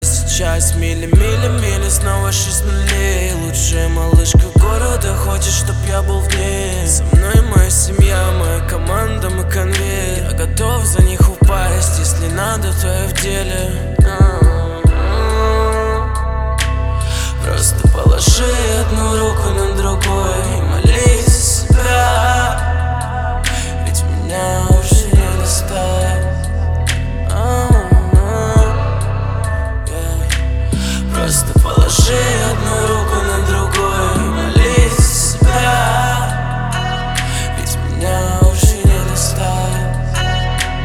мужской вокал
Хип-хоп
грустные
русский рэп
спокойные
спокойная музыка